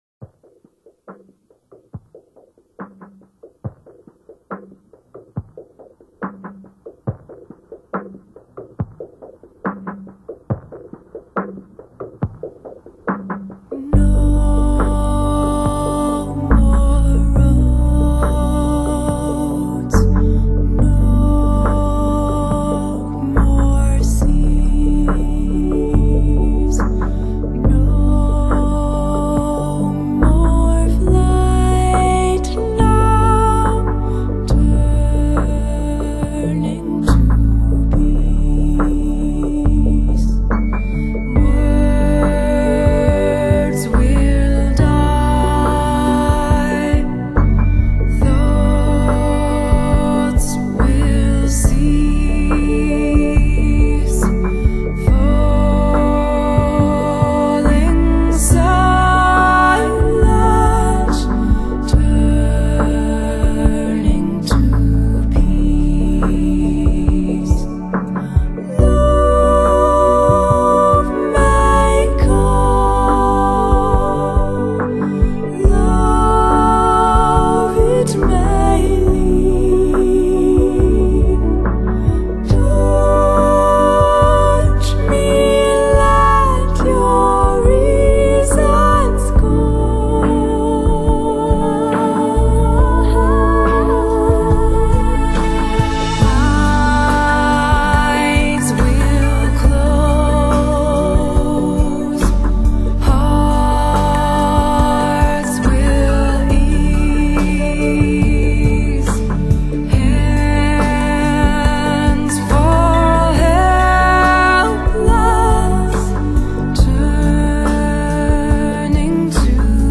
音乐类别：天籁和声